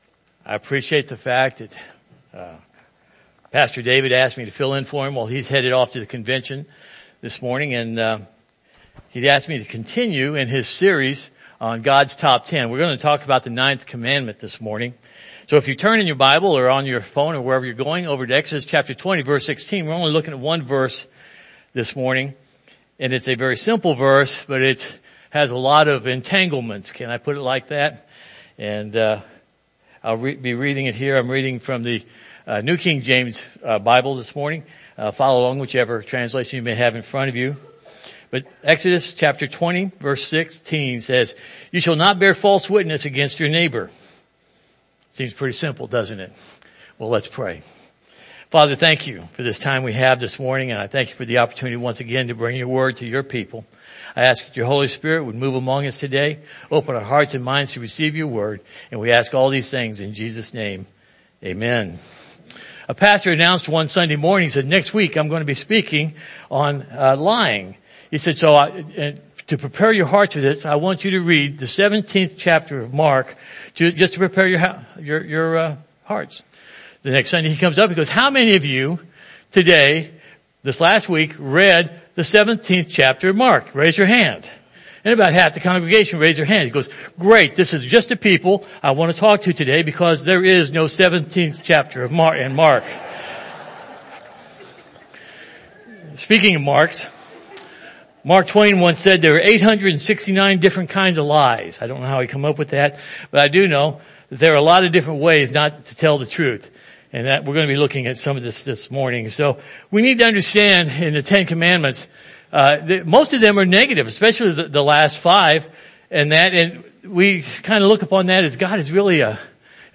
God's Top Ten Service Type: Morning Service « Acts